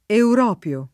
[ eur 0 p L o ]